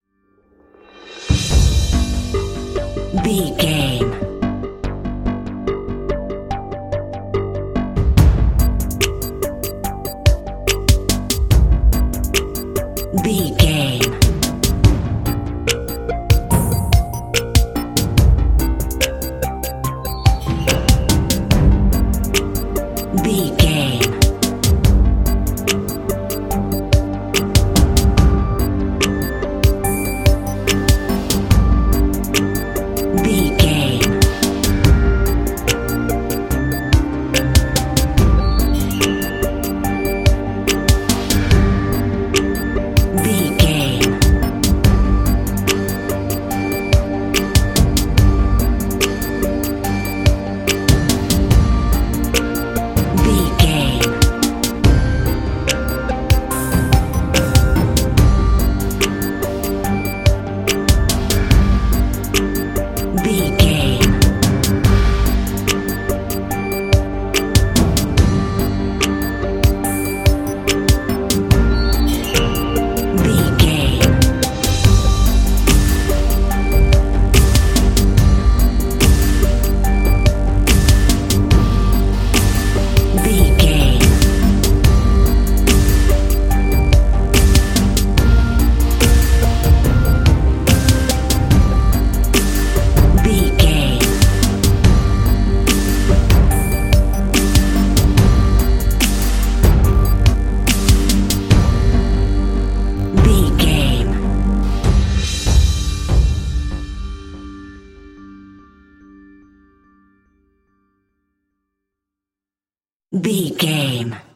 Epic / Action
Thriller
Aeolian/Minor
tension
scary
synthesiser
drums
strings
contemporary underscore